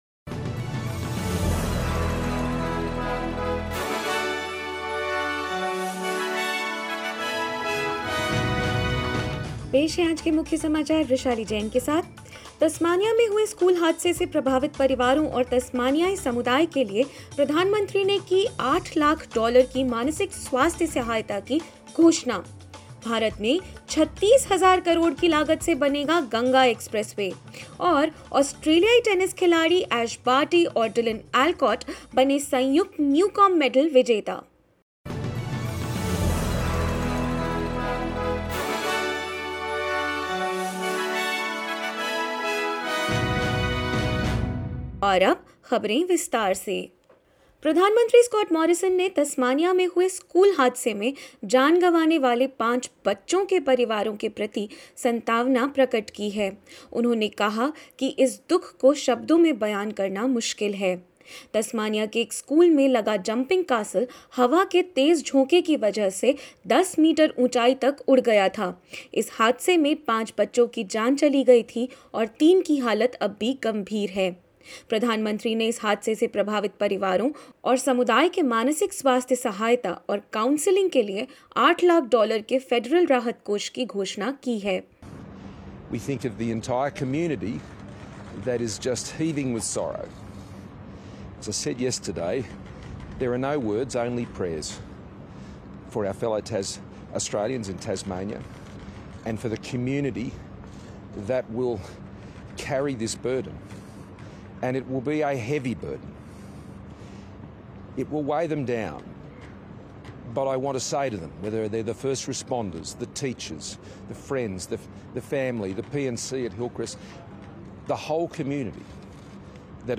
In this latest SBS Hindi news bulletin of Australia and India: PM Scott Morrison has announced a mental health aid of $800,000 for the families and wider community of victims of Tasmania school tragedy; COVID-19 cases on the rise in Australia as states implement easing of restrictions and more.